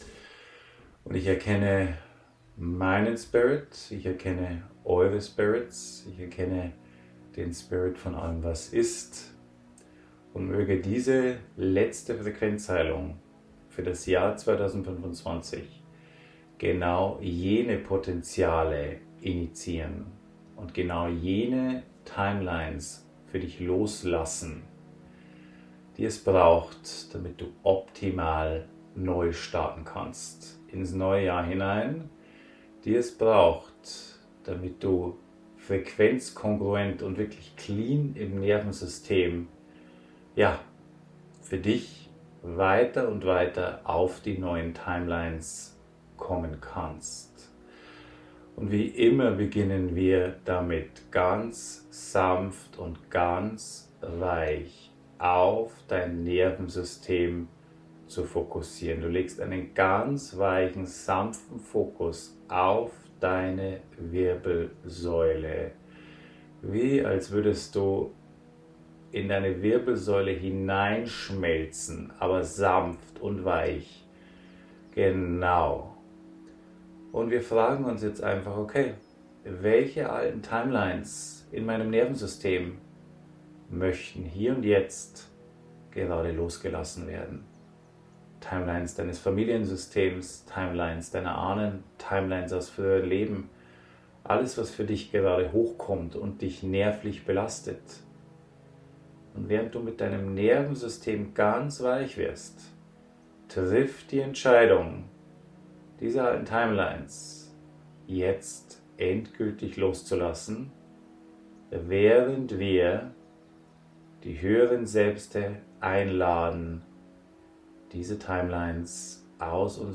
Viele von uns fühlen sich am Ende dieses Jahres ausgebrannt und leer und warten noch immer auf den großen Shift in einem gewissen Lebensbereich, der sich "stuck" auf einer alten Zeitlinie anfühlt. In dieser Abschlussfrequenzheilung 2025 als Teil eines Instagram-Lives unterstützen wir die Körper-Systeme dabei, festgefahrene Frequenz-Strukturen weiter loszulassen, aktivieren die Frequenz des Christusbewusstsein im Herzraum und dehnen dieses in die Gesamtheit des physischen Körpers aus, was dich schwungvoll und hochfrequent in das neue Jahr 2026 trägt.